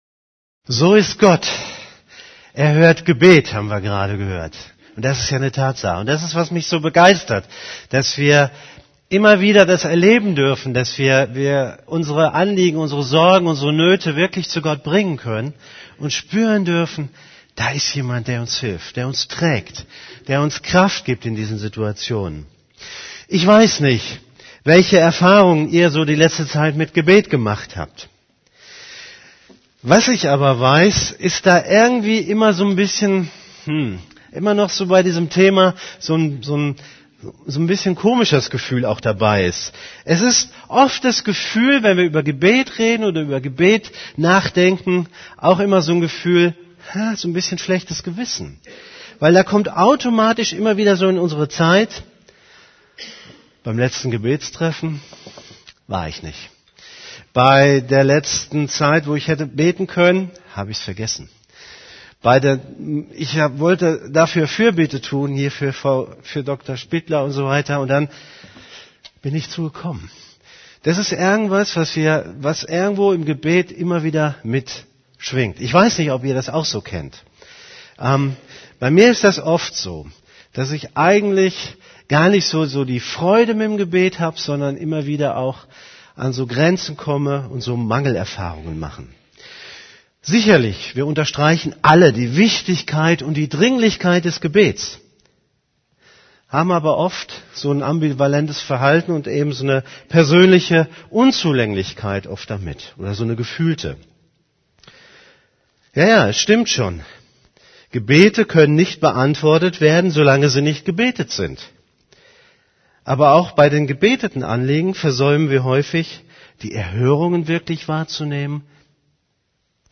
> Übersicht Predigten Als Kind Gottes beten Predigt vom 17.